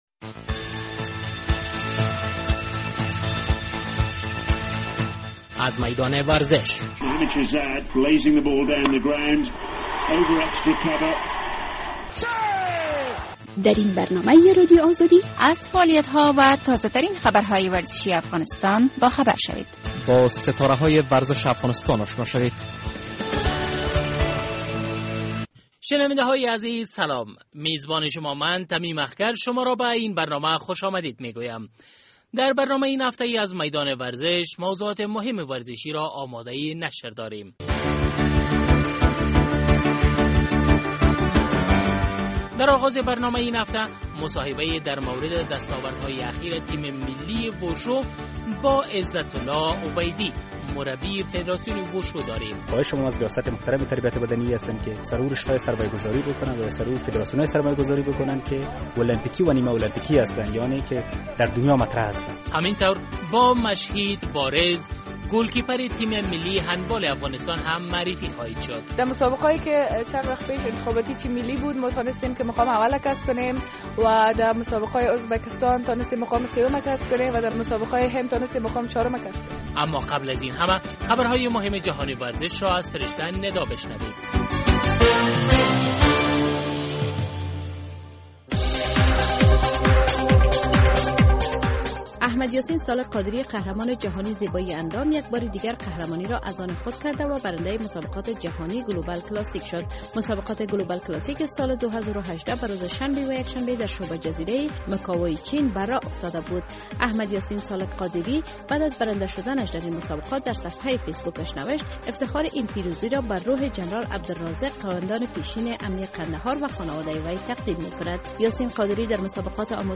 برنامۀ ورزش